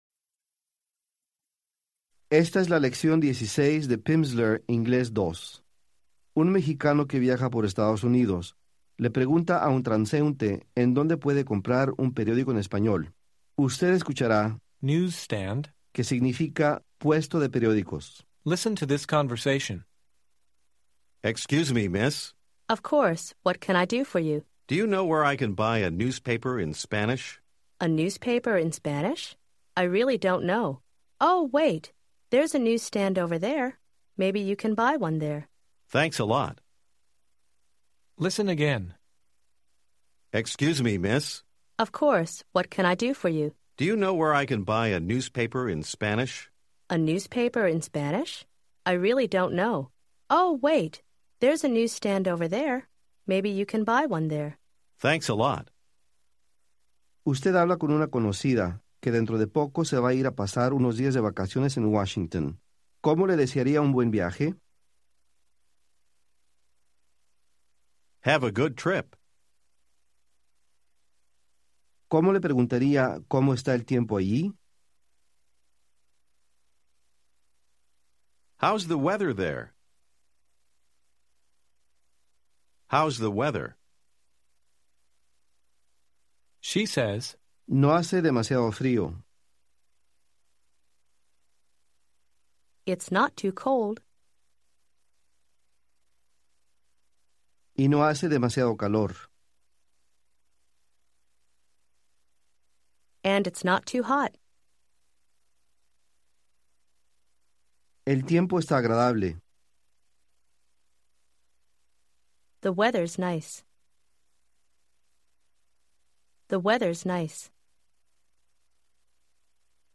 Unabridged — 2 hours, 32 minutes
Audiobook